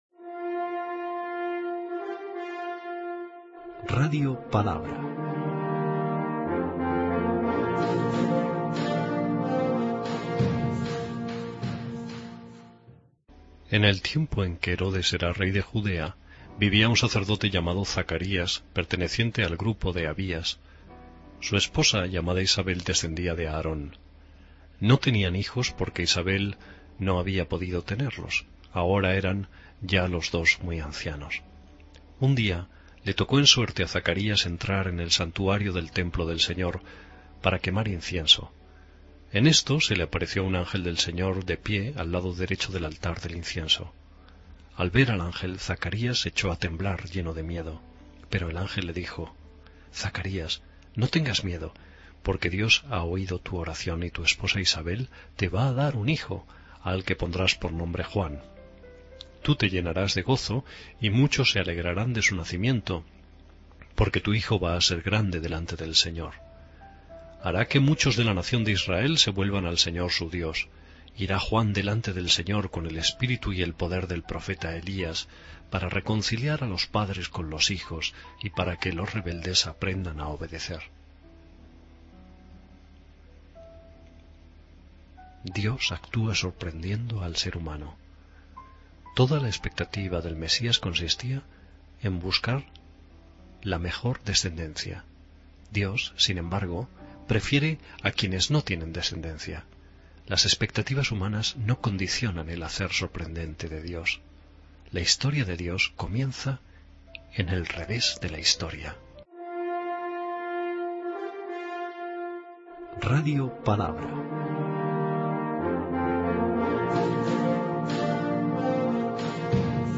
Lectura del santo evangelio según san Lucas 1,5-25